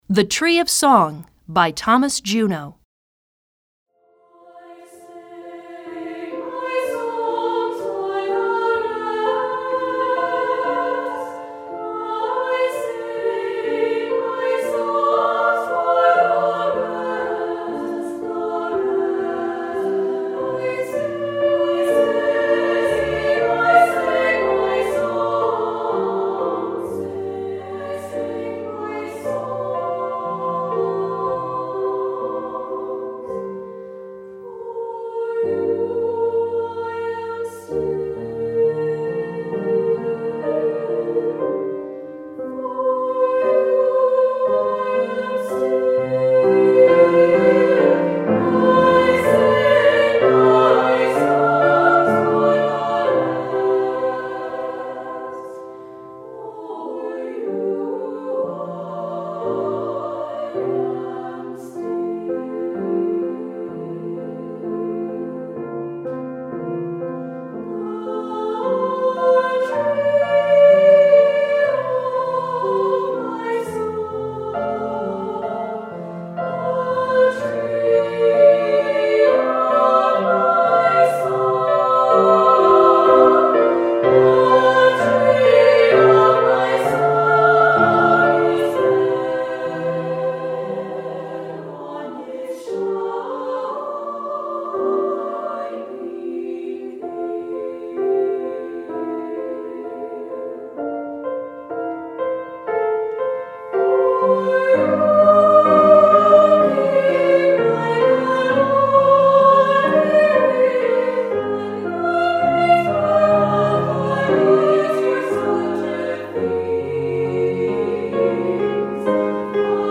Choeur SSA et Piano